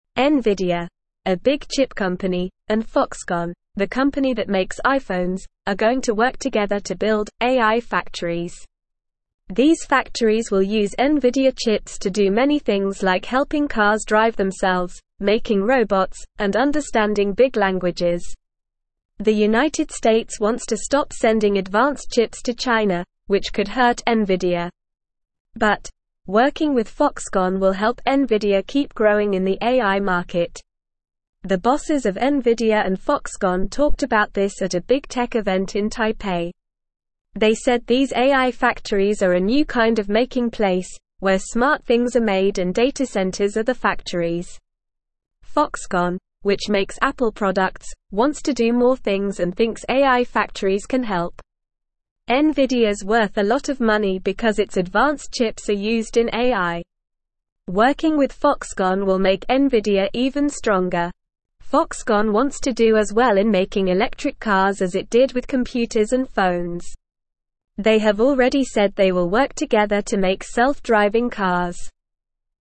Normal
English-Newsroom-Beginner-NORMAL-Reading-Nvidia-and-Foxconn-Join-Forces-to-Build-AI-Factories.mp3